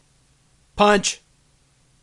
漫画SFX " 平
标签： 效果 卡通 声乐
声道立体声